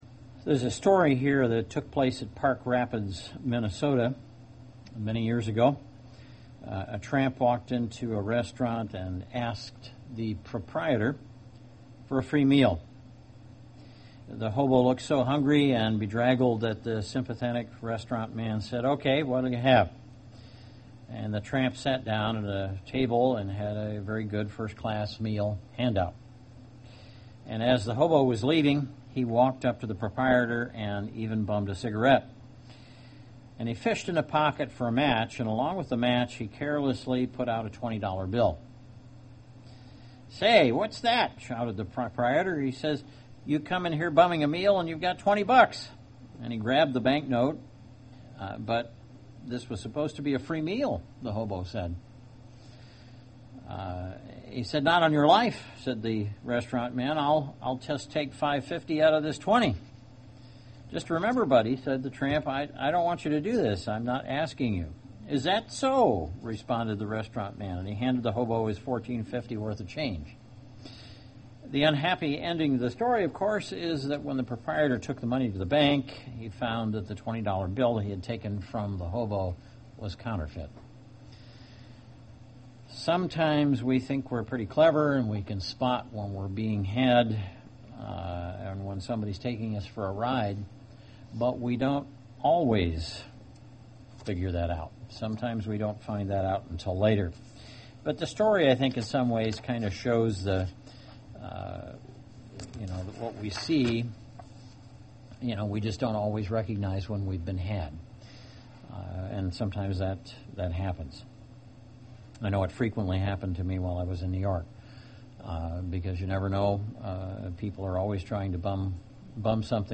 Sometimes we think we're clever and can tell when we're being had, but we don't always recognize it. This sermon continues looking at dangerous and deceiving doctrines that we should be aware of.